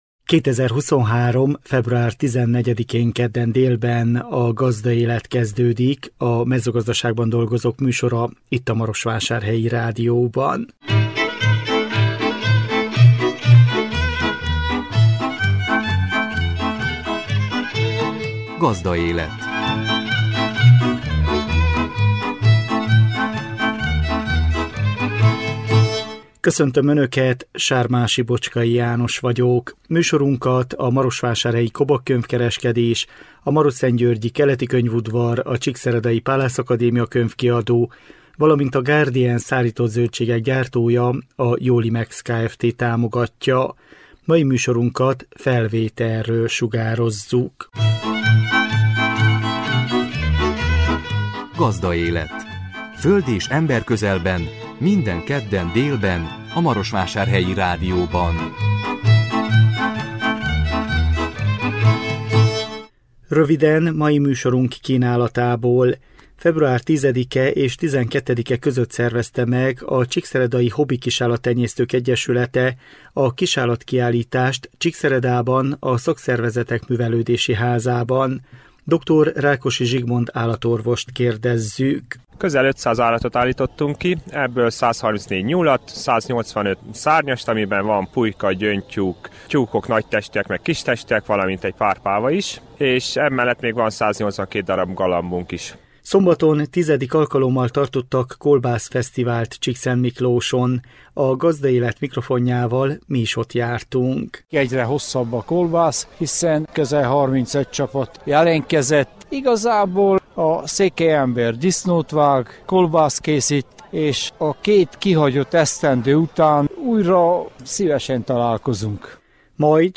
Szombaton X. alkalommal tartottak kolbászfesztivált Csíkszentmiklóson. A Gazdaélet mikrofonjával mi is ott jártunk.